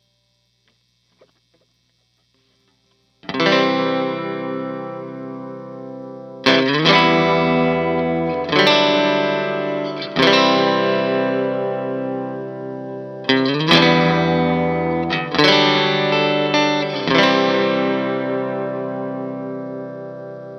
Guitar_Desert_140bpm_Emin
Guitar_Desert_140bpm_Emin.wav